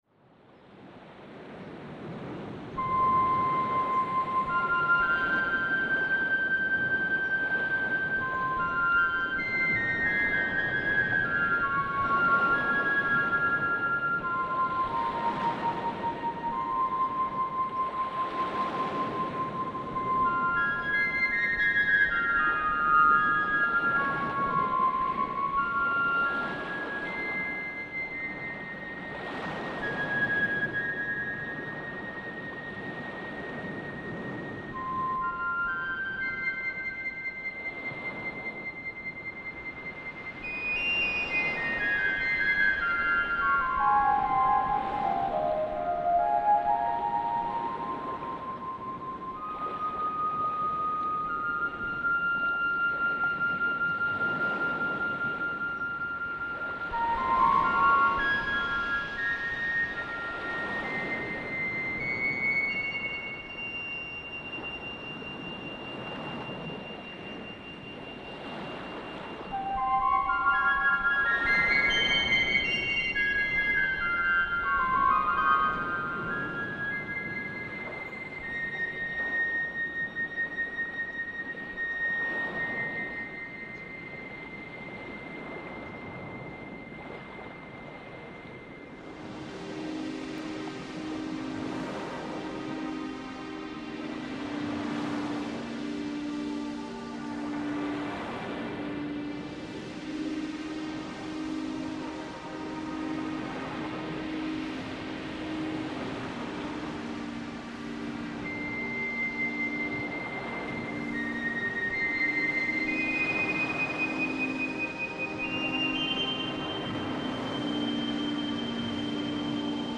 MUSIC
available with music or nature soundtrack